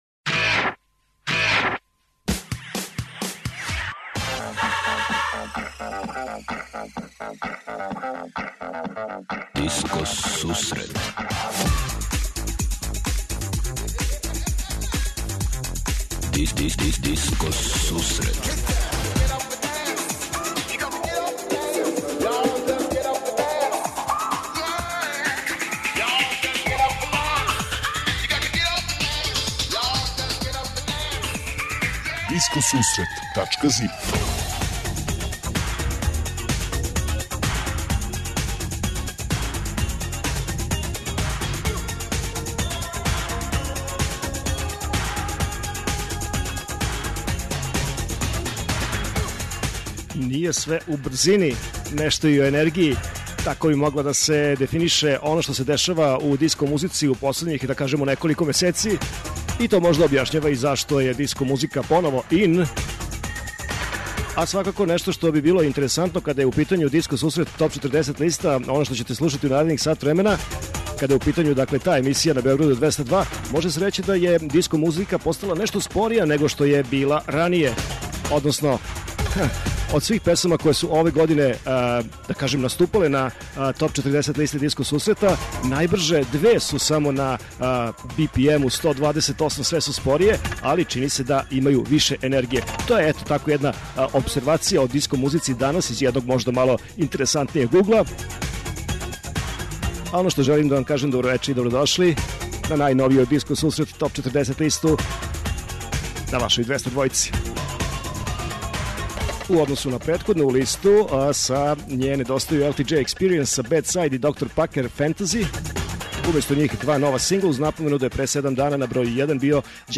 Eмисија посвећена најновијој и оригиналној диско музици у широком смислу.
Заступљени су сви стилски утицаји других музичких праваца - фанк, соул, РнБ, итало-диско, денс, поп. Сваке среде се предстаља најновија, актуелна, Топ 40 листа уз непосредан контакт са слушаоцима и пуно позитивне енергије.